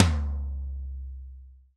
Index of /90_sSampleCDs/Sound & Vision - Gigapack I CD 1 (Roland)/KIT_REAL m 9-12/KIT_Real-Kit m11
TOM TOM107.wav